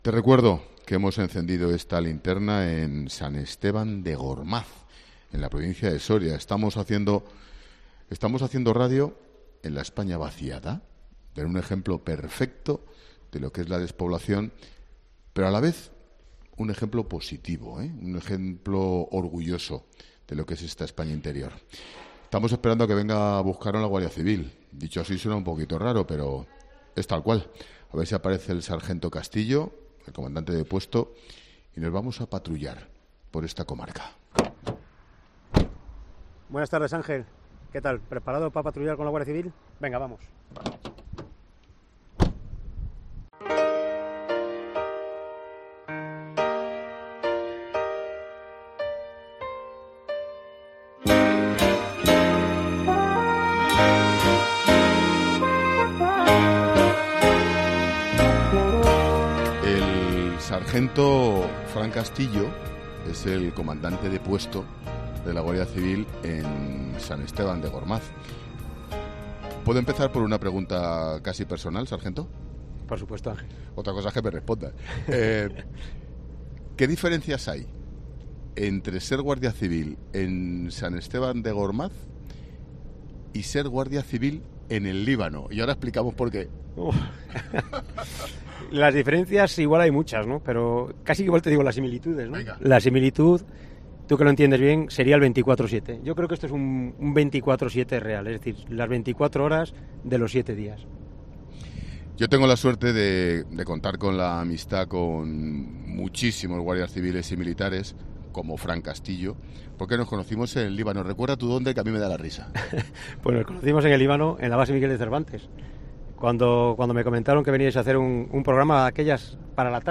Un equipo de 'La Linterna' se sube a un coche de la Guardia Civil para saber cómo se patrulla en la 'España Vaciada'.
Hoy 'La Linterna' se enciende desde la 'España Vaciada', en San Esteban de Gormaz, provincia de Soria.